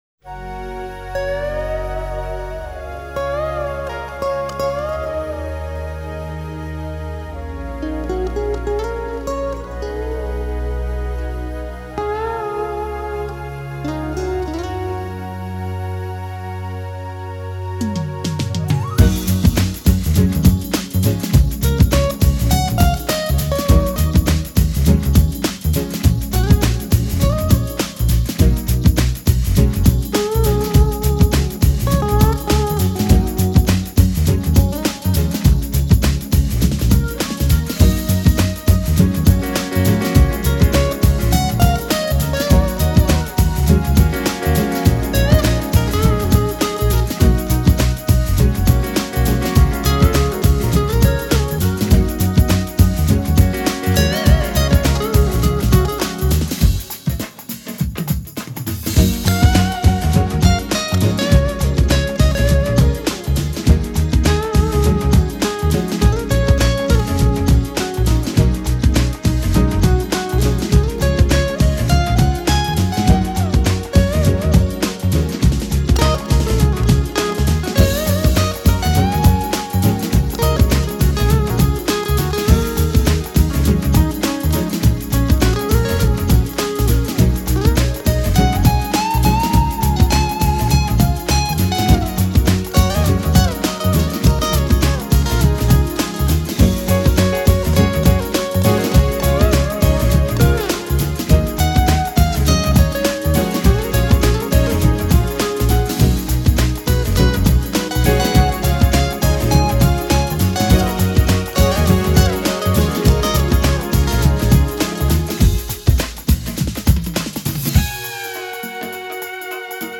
Smooth Jazz trio